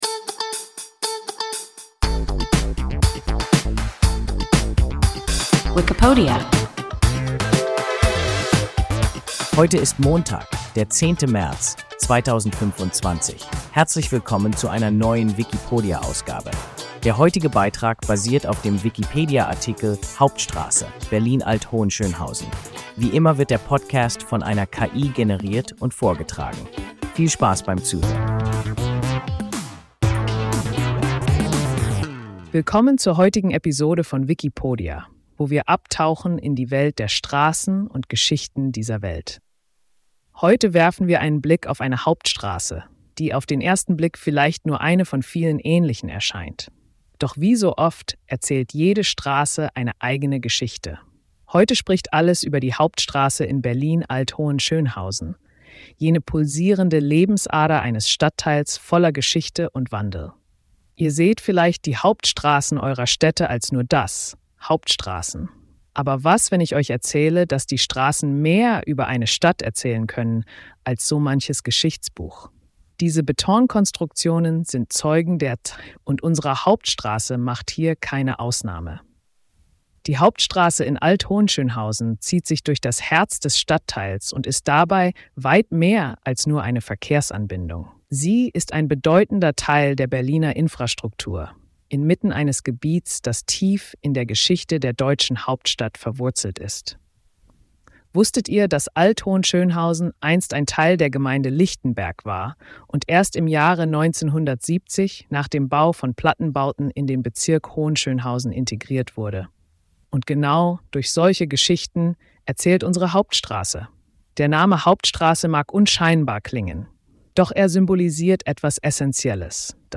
Wikipodia – ein KI Podcast